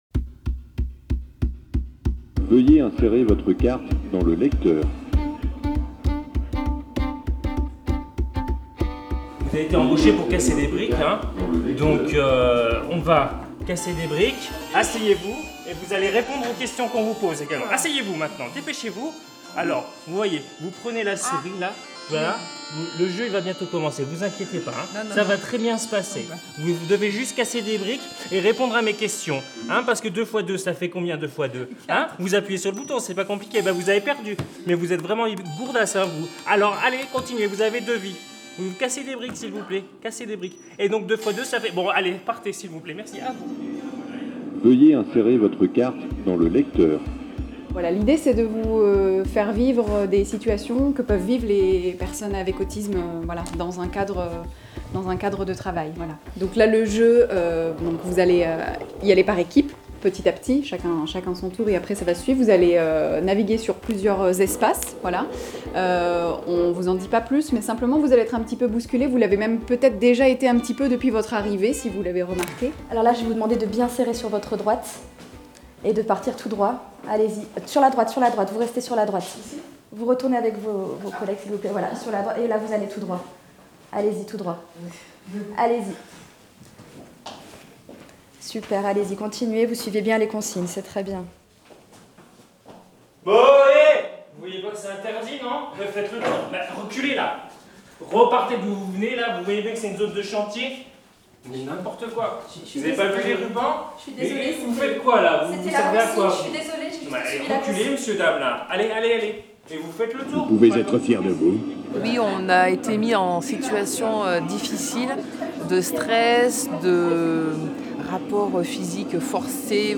14 janvier 2019 16:39 | Interview, reportage